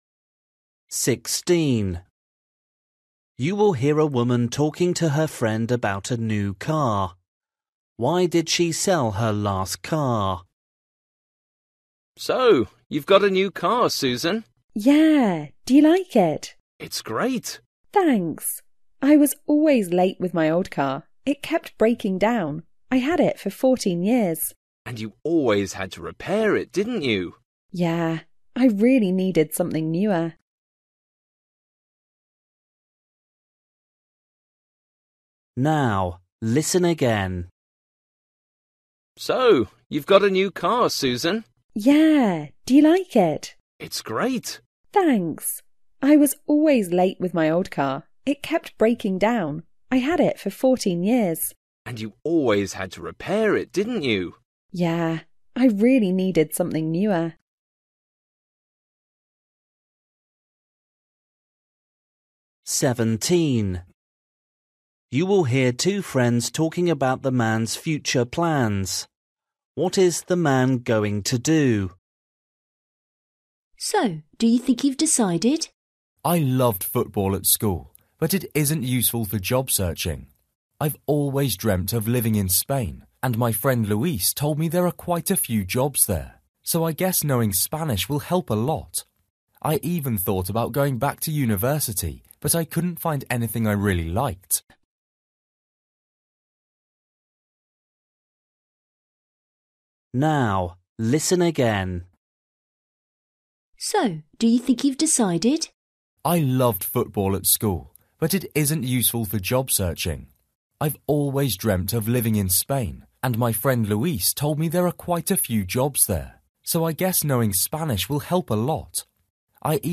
Listening: everyday short conversations
16   You will hear a woman talking to her friend about her new car. Why did she sell her last car?
17   You will hear two friends talking about the man’s future plans. What is the man going to do?
18   You will hear a couple talking about a video. What’s the video of?
19   You will hear a woman calling a friend. What’s her problem?